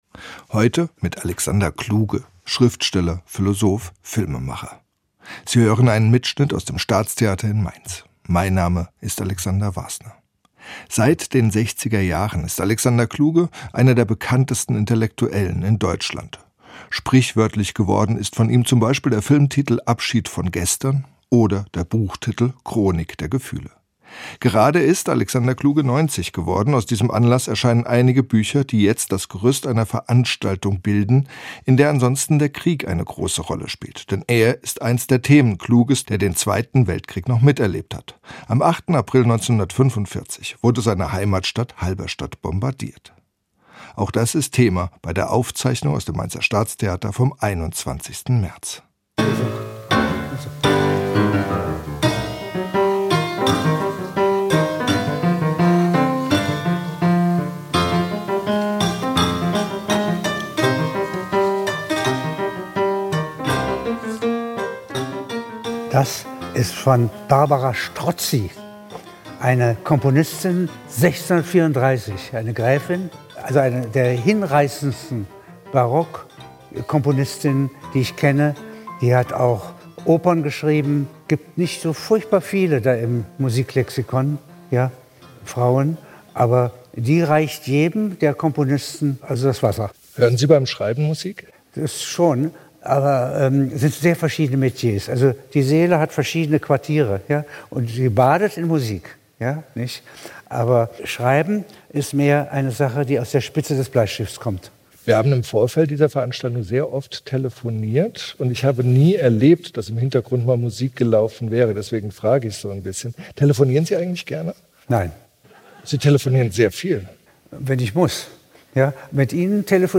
Aus dem Archiv: Gespräch zum 90. Geburtstag
Ein Gespräch von 2022.
im-unruhigen-garten-der-seele-ein-leseabend-mit-alexander-kluge.mp3